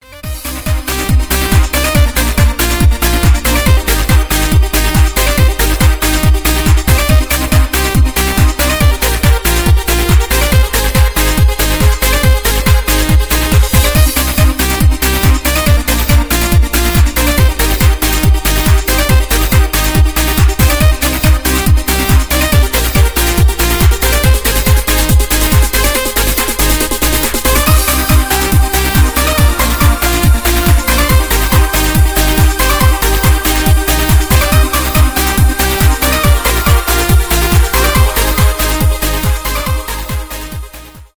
транс , edm
без слов